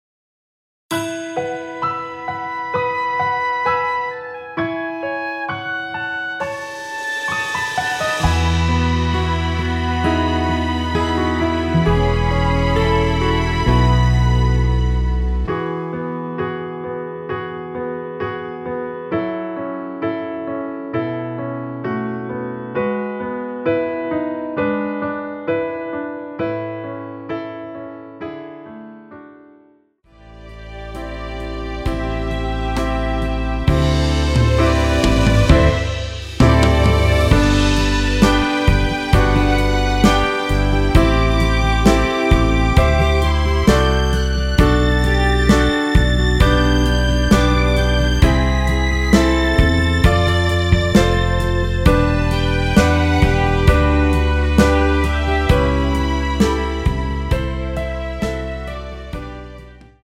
원키에서(+6)올린 MR입니다.
앞부분30초, 뒷부분30초씩 편집해서 올려 드리고 있습니다.